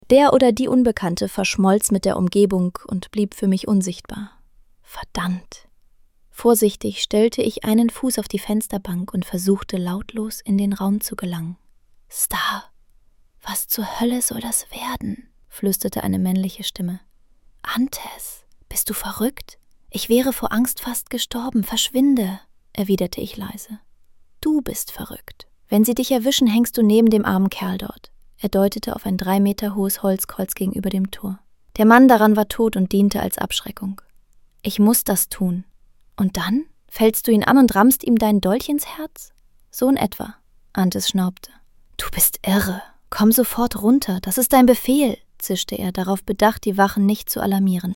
Neue Hörprobe
Gibts das Bettgeflüster auch in einem strengeren Ton?